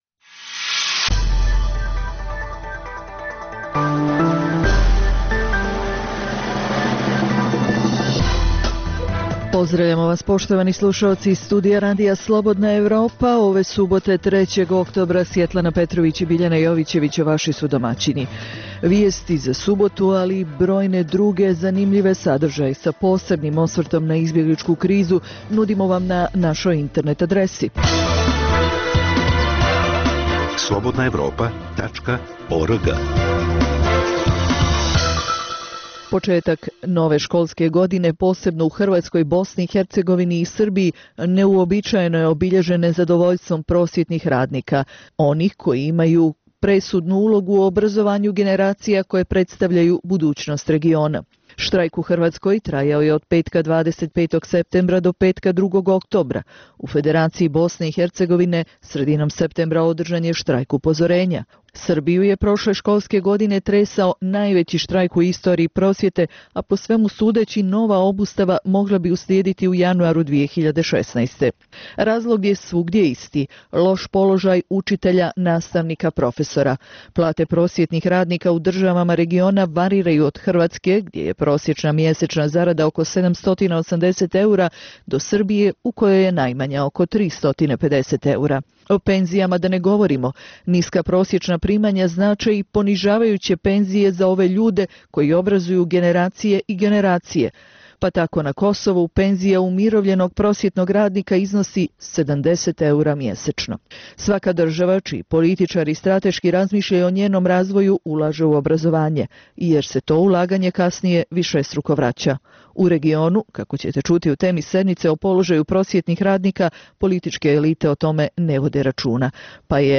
Emisija o dešavanjima u regionu (BiH, Srbija, Kosovo, Crna Gora, Hrvatska) i svijetu. Prvih pola sata emisije sadrži regionalne i vijesti iz svijeta, te temu sedmice u kojoj se analitički obrađuju najaktuelnije i najzanimljivije teme o dešavanjima u zemljama regiona. Preostalih pola sata emisije, nazvanih "Tema sedmice" sadrži analitičke teme, intervjue i priče iz života, te rubriku "Dnevnik", koji poznate i zanimljive osobe vode za Radio Slobodna Evropa vode